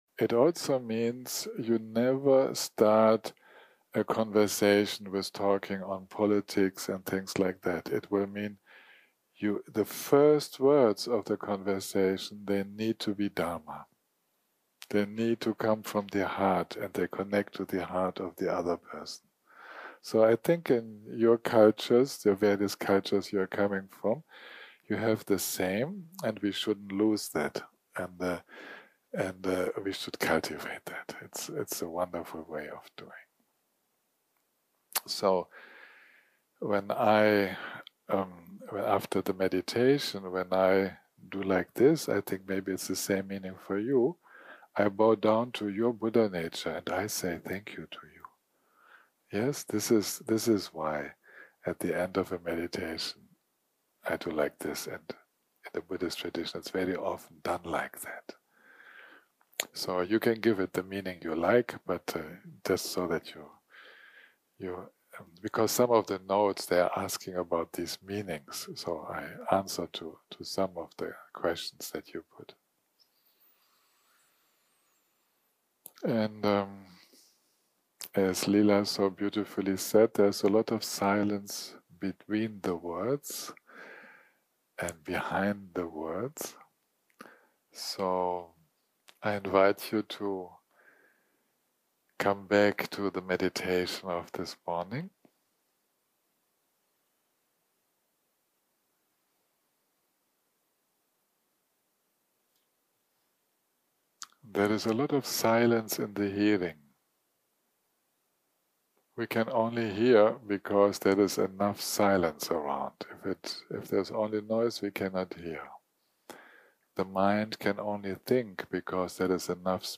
יום 6 - הקלטה 25 - בוקר - שיחת דהרמה - All apperances are mind - part 2